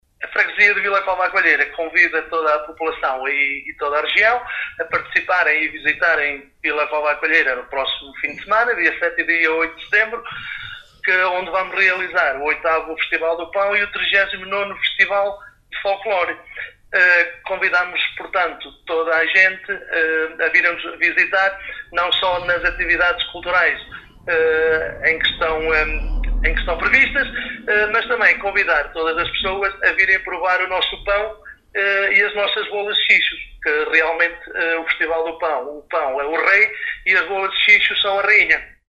Fernando Guedes, Presidente da Junta de Freguesia, deixa igualmente o convite para se deslocarem até ao 8º Festival do Pão e 39º Festival de Folclore em Vila Cova à Coelheira.